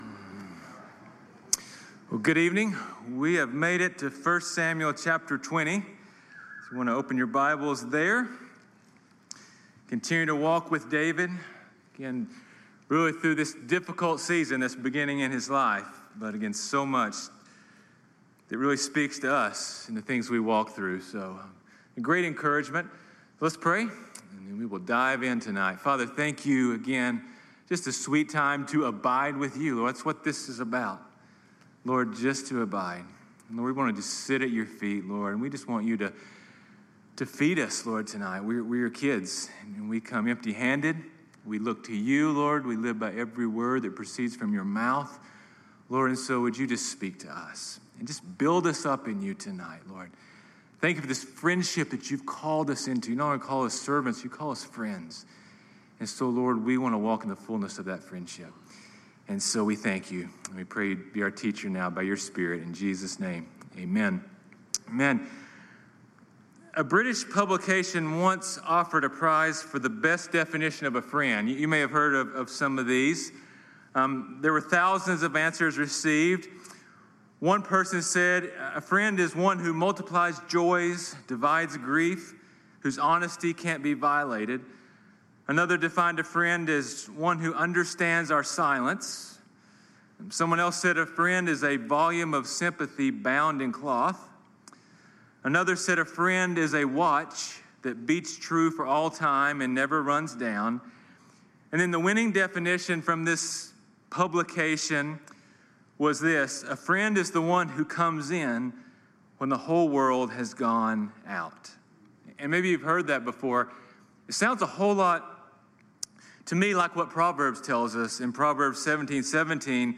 sermons
Calvary Chapel Knoxville